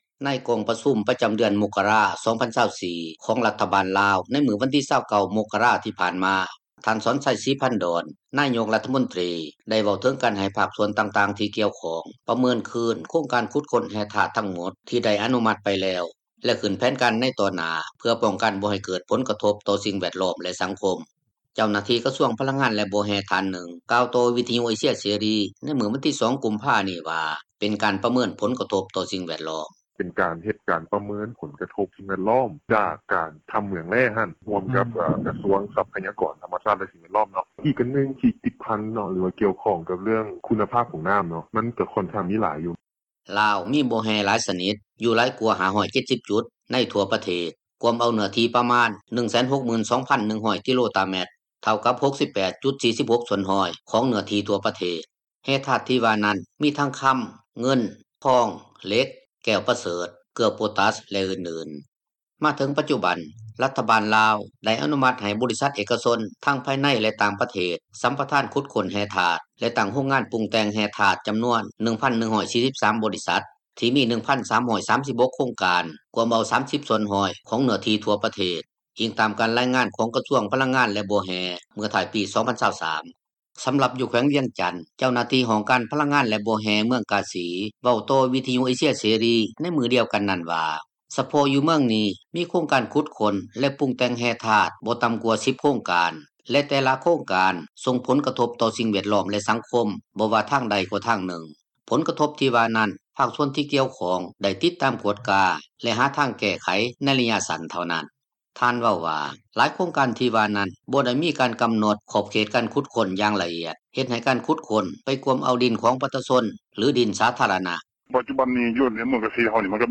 ເຈົ້າໜ້າທີ່ ກະຊວງພະລັງງານ ແລະບໍ່ແຮ່ ທ່ານນຶ່ງກ່າວຕໍ່ວິທຍຸເອເຊັຽເສຣີ ໃນມື້ວັນທີ 2 ກຸມພານີ້ວ່າ ເປັນການປະເມີນຜົລກະທົບຕໍ່ສິ່ງແວດລ້ອມ: